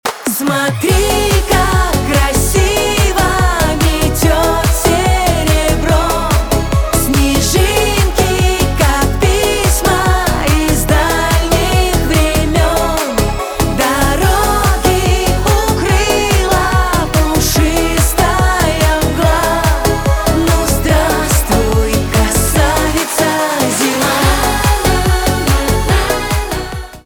поп
битовые , веселые